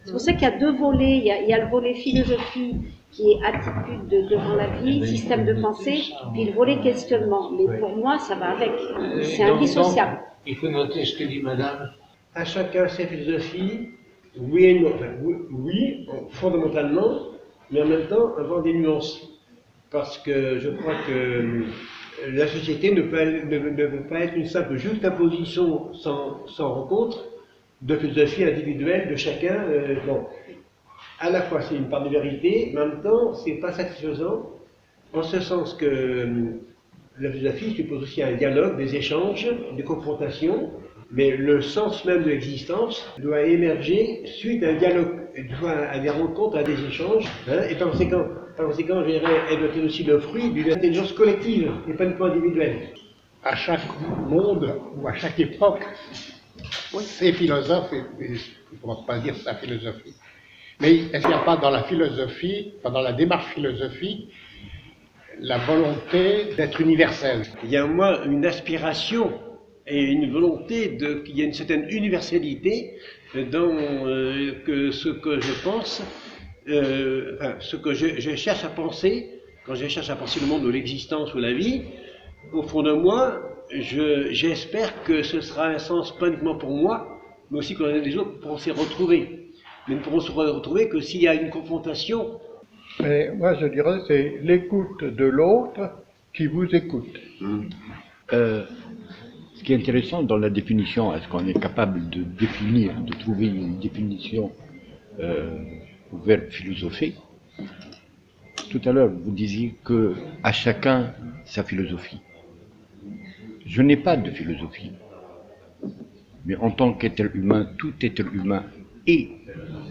café-philo-reportage.mp3